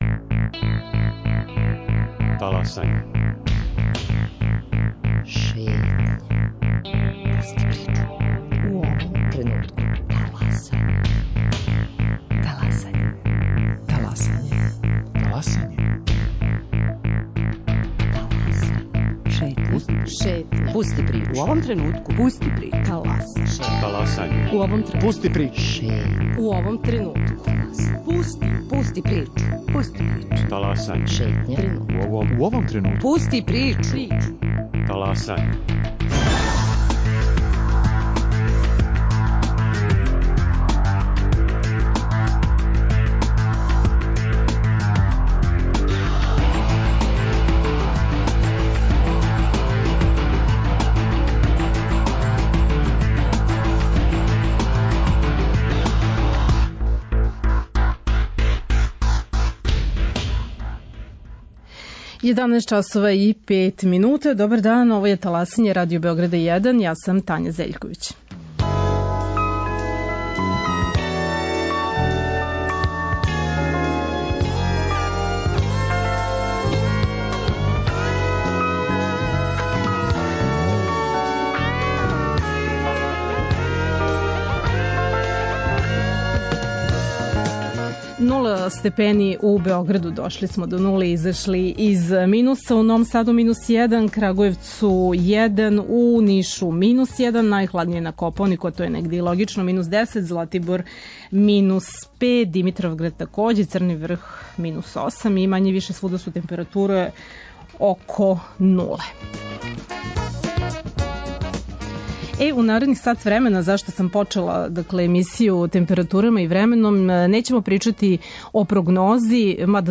Гoсти: мeтeoрoлoзи